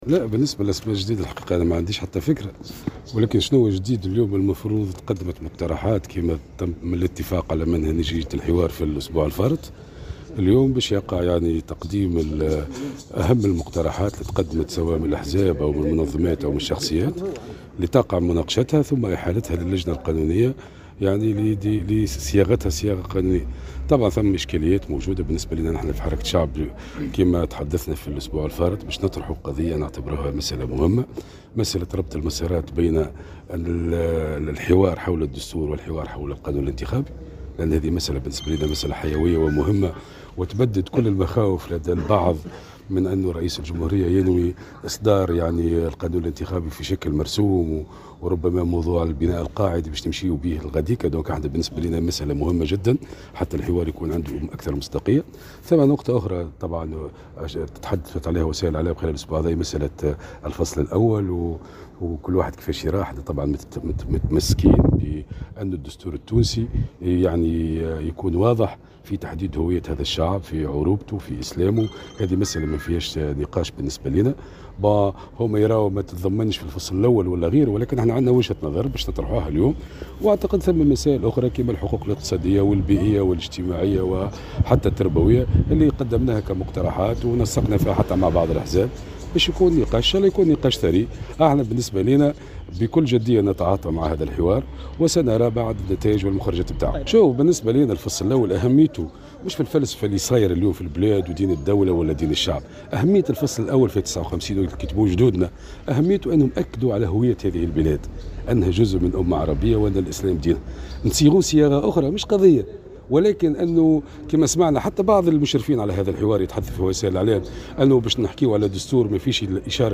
وبيّن المغزاوي في تصريح صحفي اليوم السبت بمناسبة انعقاد الاجتماع الثاني للمشاركين في الحوارصلب اللجنة الإقتصادية والاجتماعية، (تنضوي تحت الهيئة الاستشارية للجمهورية الجديدة) بدار الضيافة بقصر قرطاج، أنّ أهمية هذا التنصيص هو التأكيد على هوية البلاد بوصفها جزء من أمة عربية، الإسلام دينها.